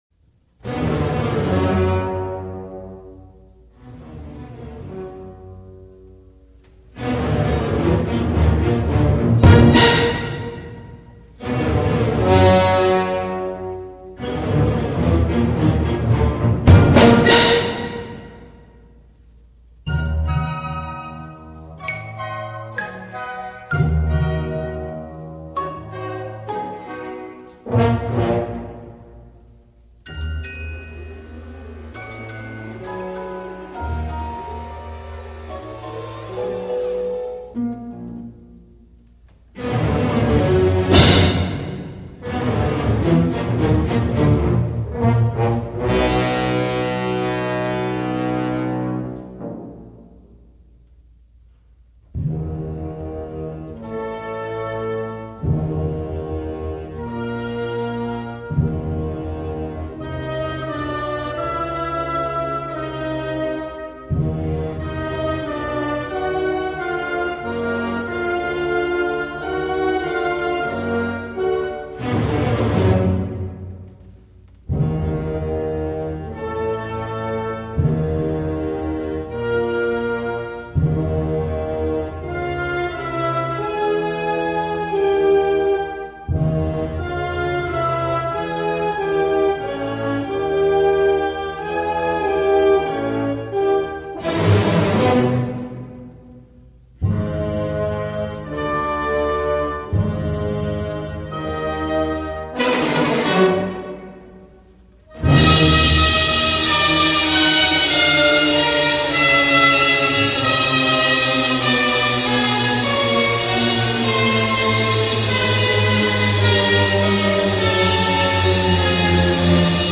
专辑格式：DTS-CD-5.1声道
动态庞大 爆得精彩
为低音质MP3